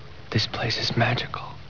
magical.wav